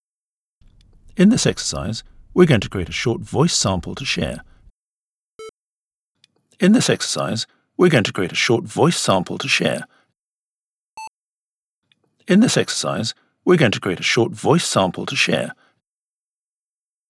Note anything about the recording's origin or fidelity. Low frequency noise - is this normal? I get quite a lot of low-frequency noise.